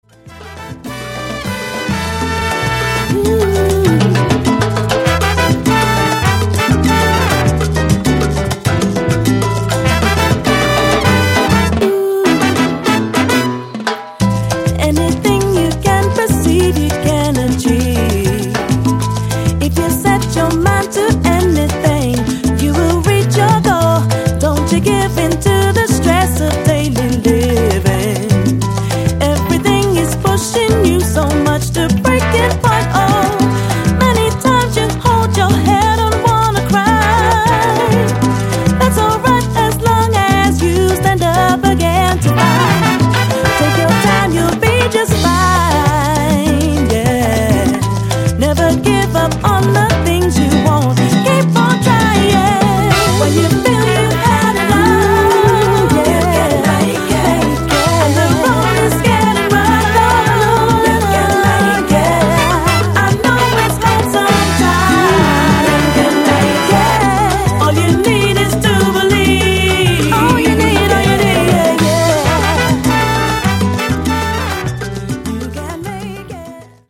ベーシスト
ジャンル(スタイル) LATIN / SALSA